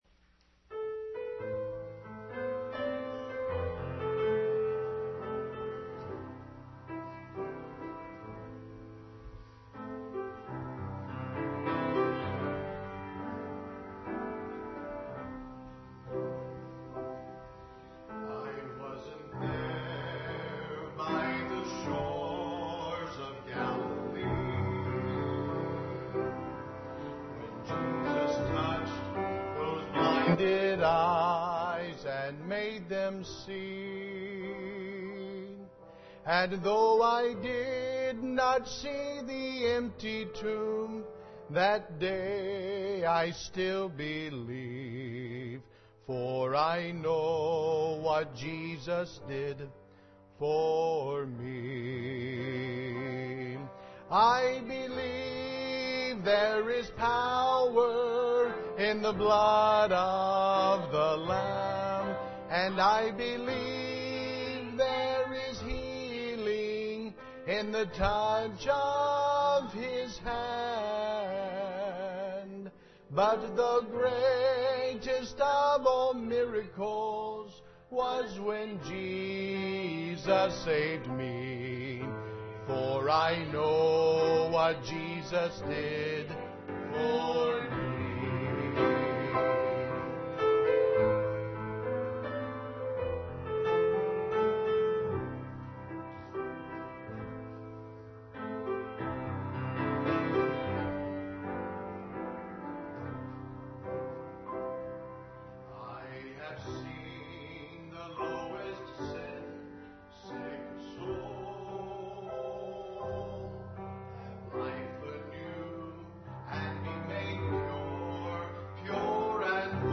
Service Type: Revival Service